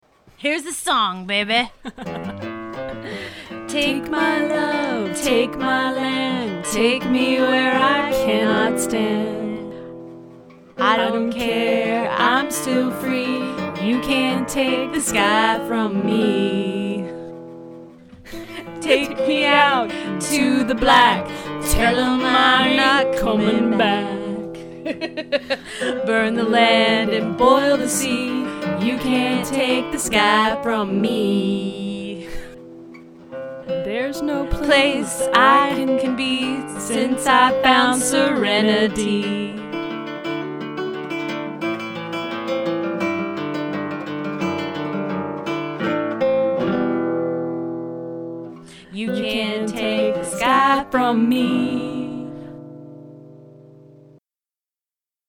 That's why I kept some of the laughter in.